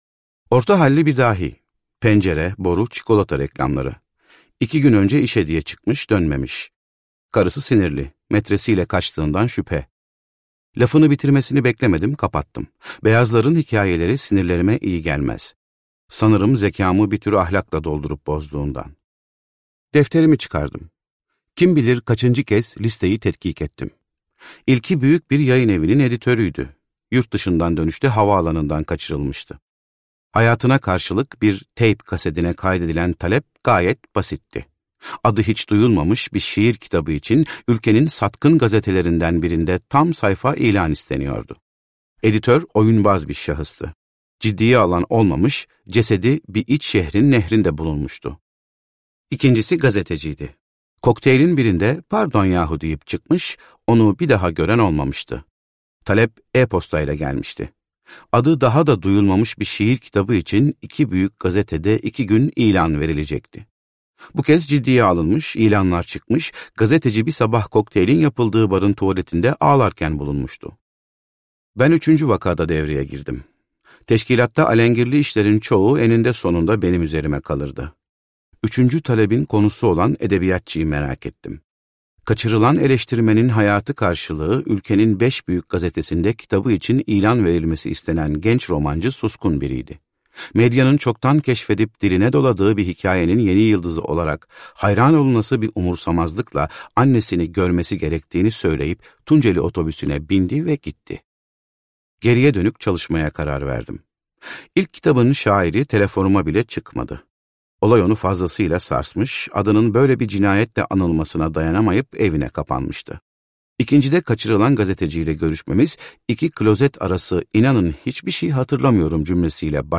Bazuka - Seslenen Kitap
Seslendiren